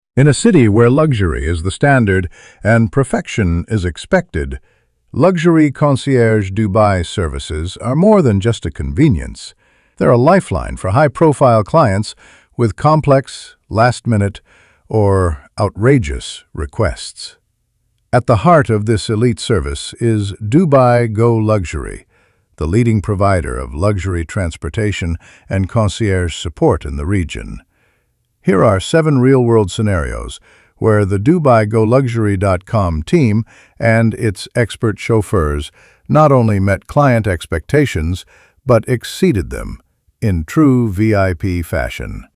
ElevenLabs_Text_to_Speech_audio-7.mp3